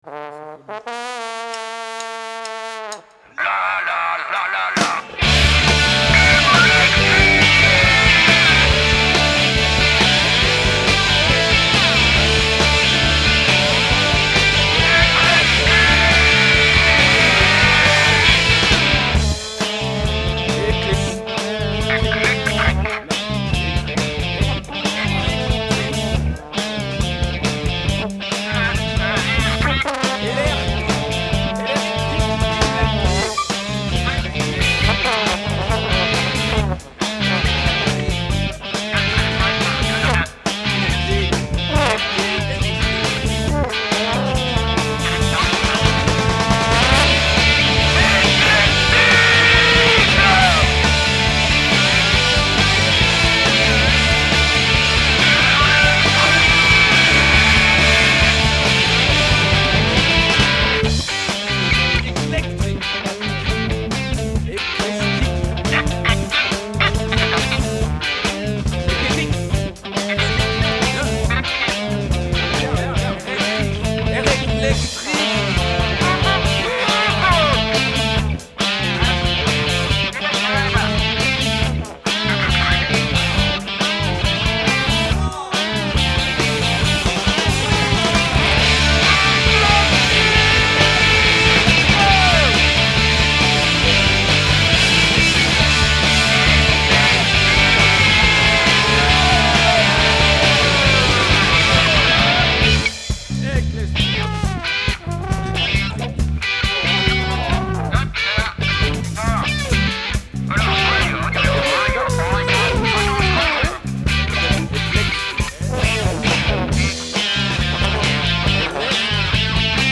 Vespa bass
Drum rules
guitar left & backing yellings